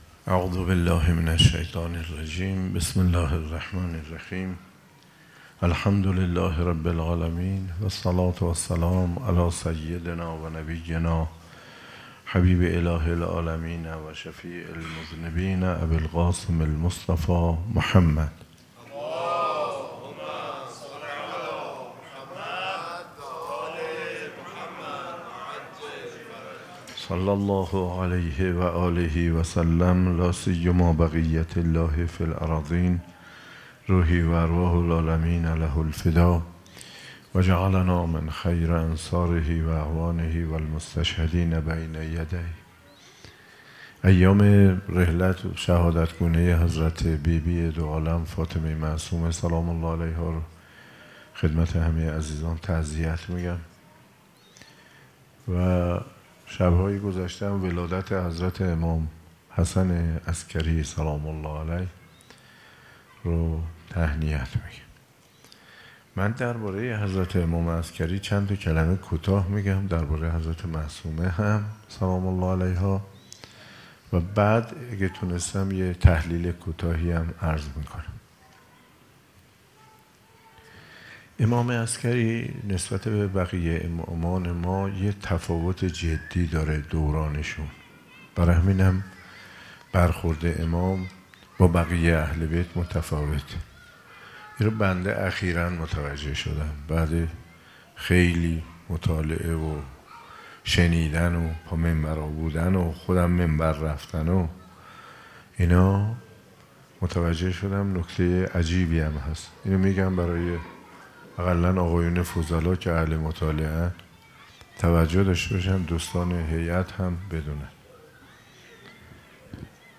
مراسم عزاداری شهادت حضرت فاطمه معصومه(س) و پنجمین یادواره شهدای گمنام حسینیه فاطمیون
سخنرانی: حجت‌الاسلام والمسلمین آقاتهرانی